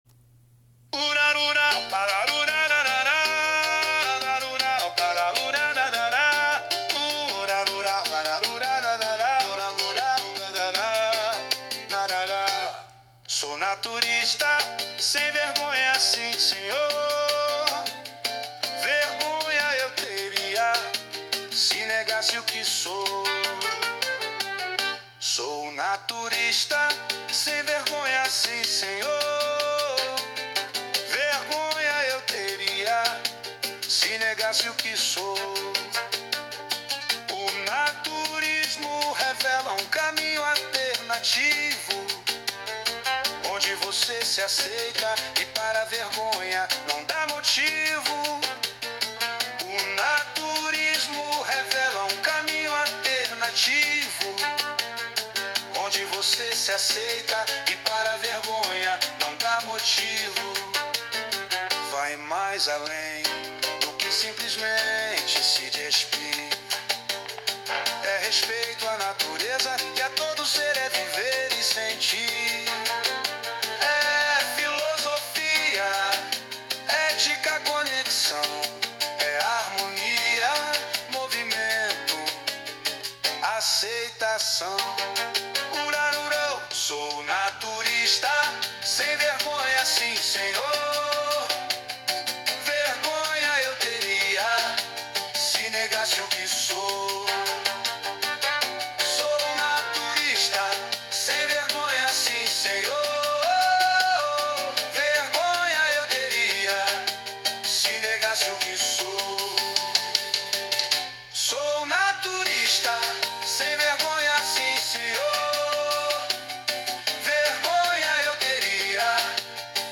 em apresentação no NAT Paraná